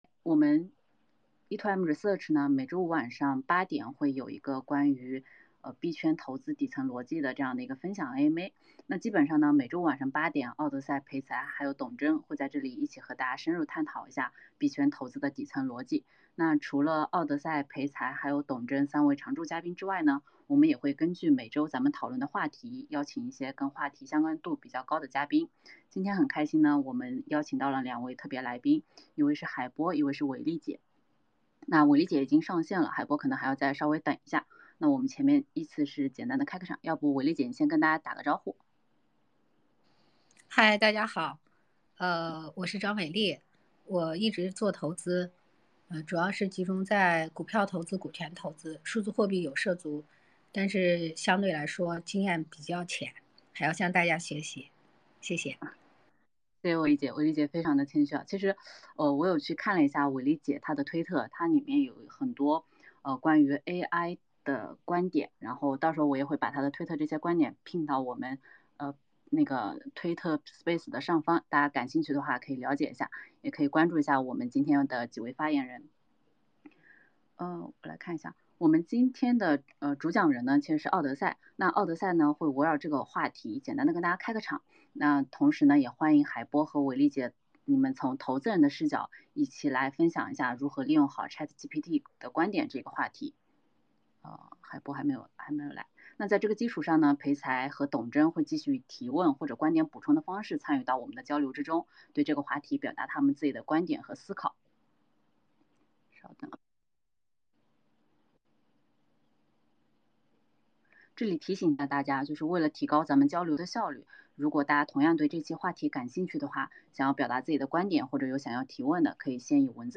本次E2M Research 的AMA，3位主讲人连同2位特邀嘉宾，一起探讨《作为投资人，如何利用好ChatGPT》 &nbsp